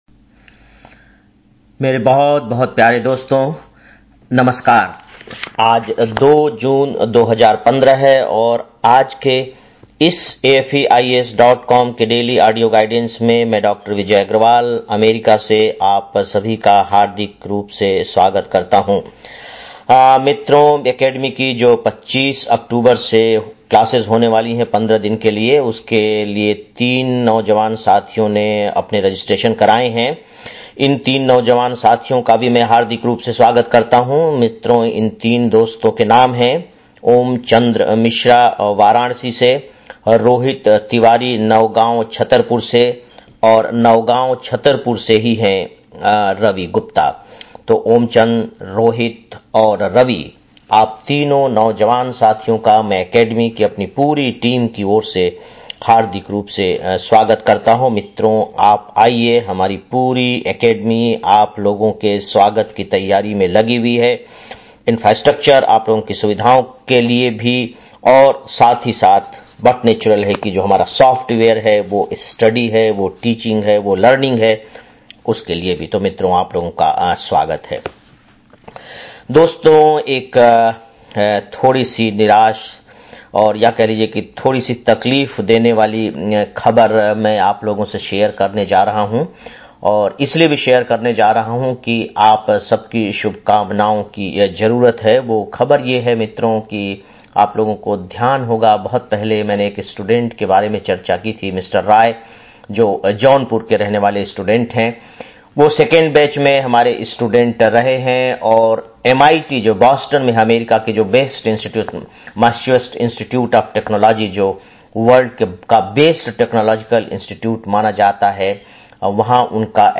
02-06-15 (Daily Audio Lecture) - AFEIAS